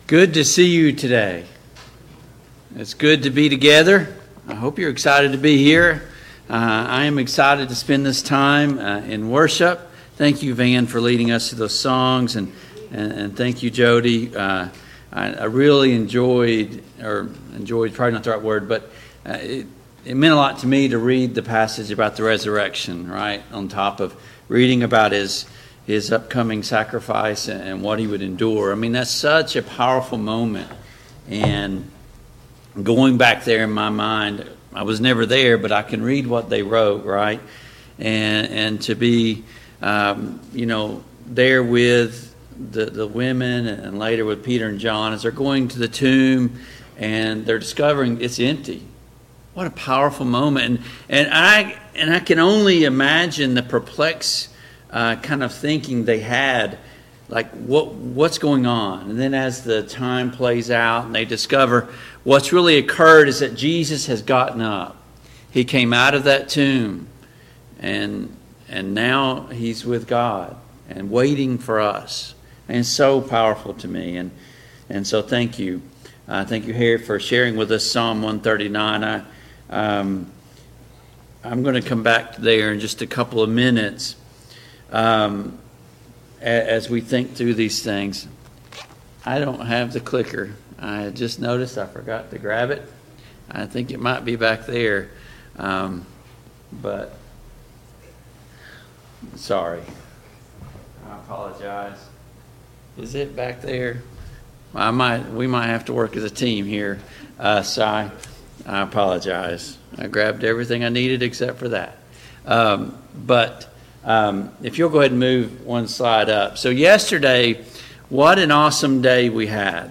Service Type: AM Worship Download Files Notes Topics: Creation vs Evolution « 10.